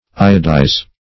Iodize \I"o*dize\, v. t. [imp.